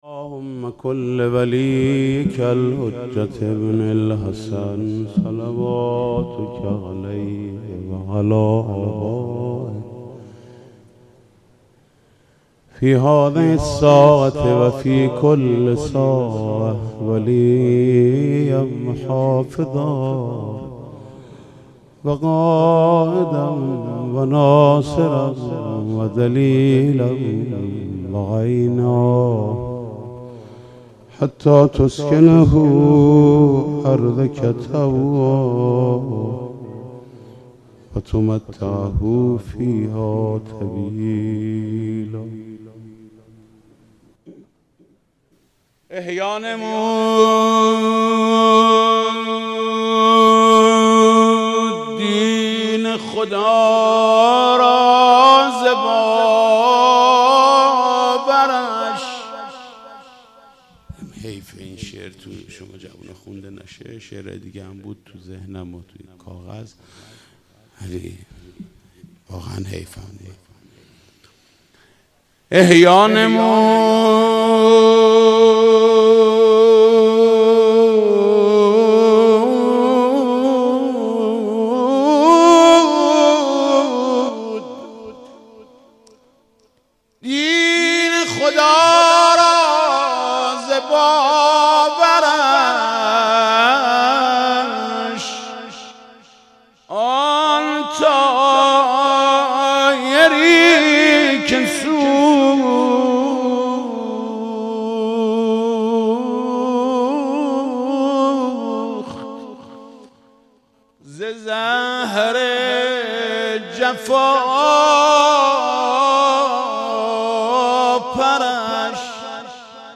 صوت/حاج محمود کریمی؛روضه شهادت امام صادق (ع)